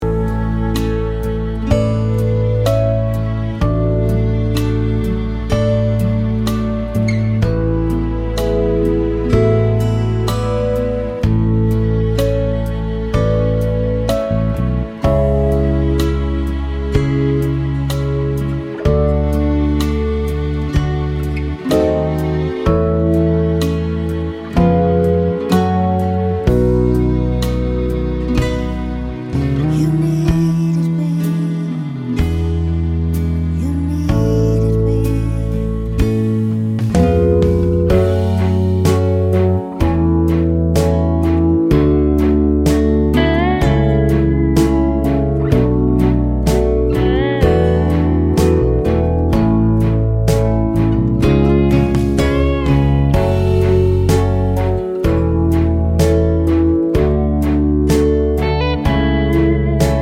no Backing Vocals Easy Listening 3:32 Buy £1.50